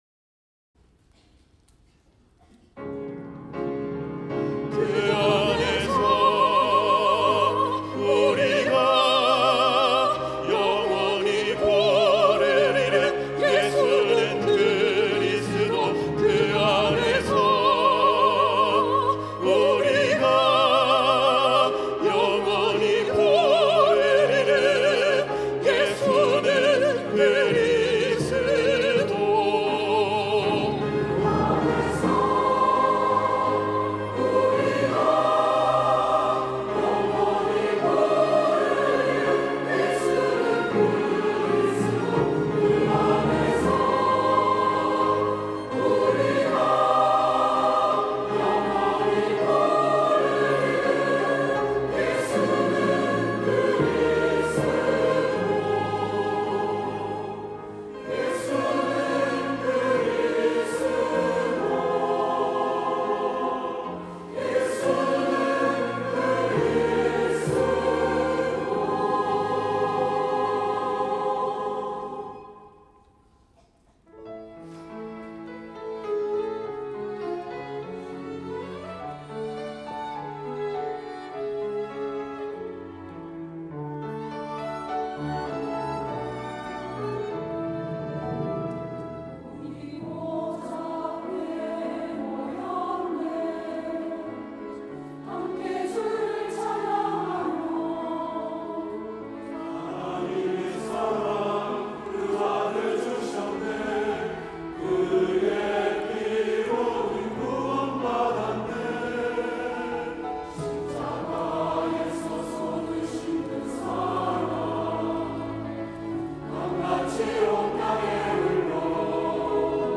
2부 찬양대
하나교회 설교와 찬양 경배와 찬양 2부 찬양대